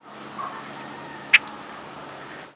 Flight call description Variable, with two basic types of flight calls. A dry, husky, typically rising "khek" (or "khrk" or "tek") and a low, hollow, popping "pwuk" (or "phewk" or "prk"). The latter call often has somewhat whiny and expressive, more pure-toned, variations.
"Khek" call from bird in flight.
Similar species Most calls lower-pitched than other grackles or blackbirds and often with a distinctive hollow-sounding quality.